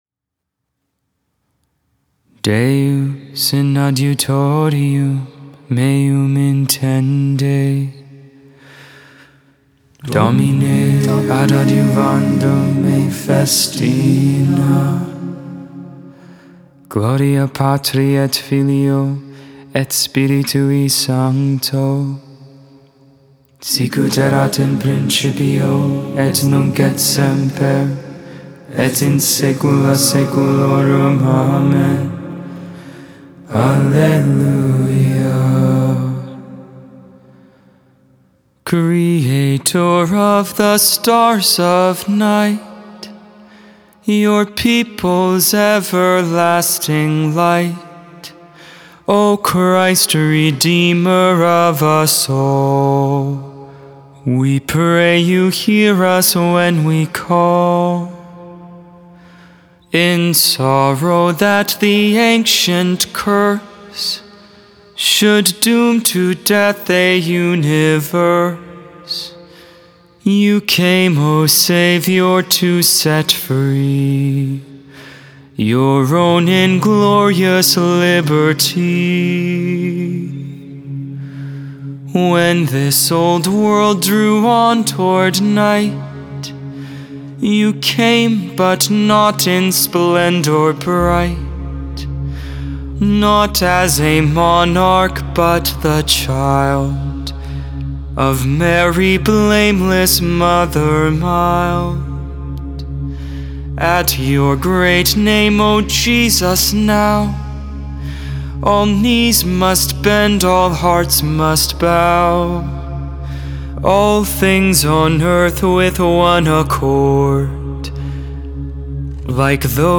11.26.22 Vespers, Saturday Evening Prayer
Hymn